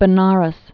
(bə-närəs, -ēz)